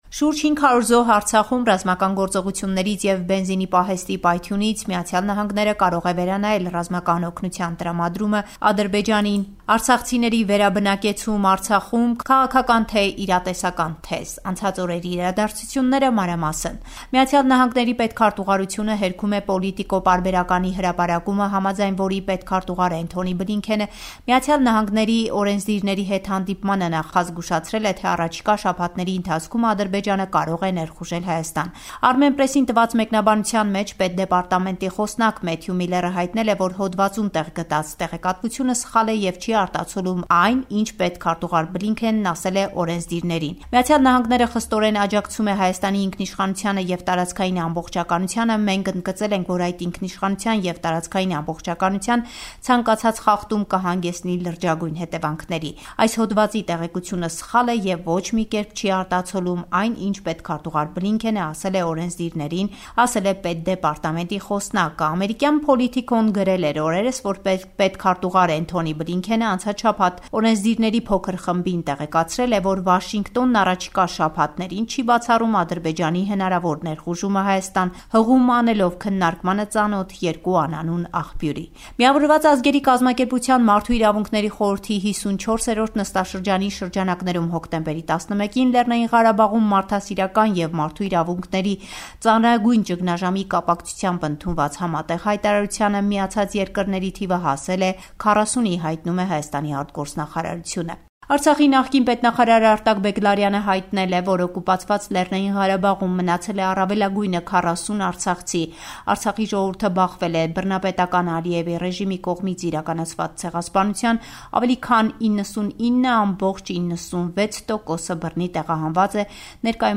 SBS Armenian